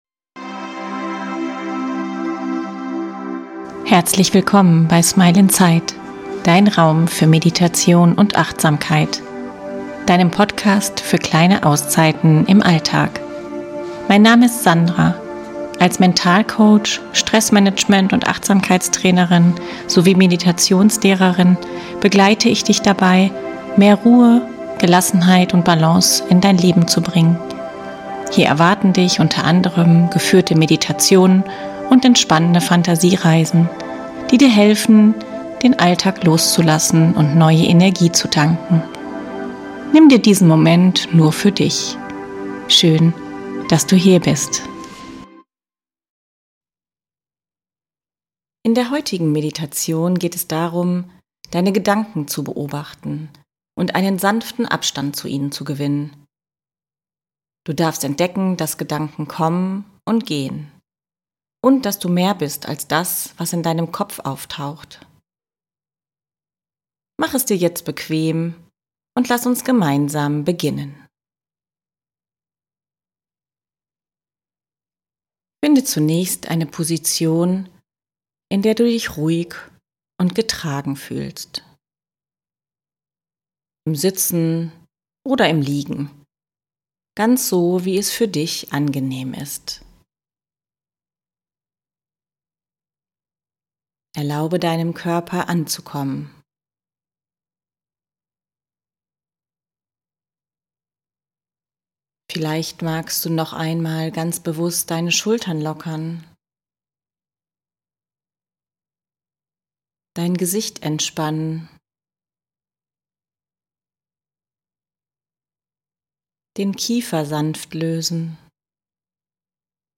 In dieser geführten Meditation lade ich dich ein, einen Schritt zurückzutreten und deine Gedanken aus einer neuen Perspektive zu betrachten. Statt dich in ihnen zu verlieren, darfst du lernen, sie sanft zu beobachten… ohne Bewertung… ohne Widerstand.